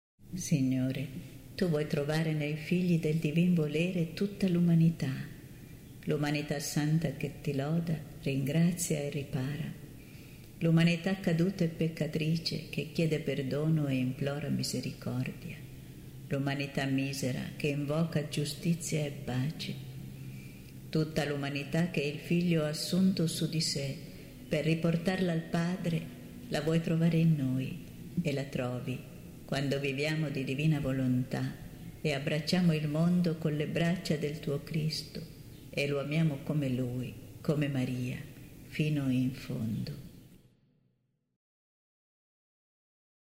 n. 21 preghiera mp3 – Umanità nel Fiat canto mp3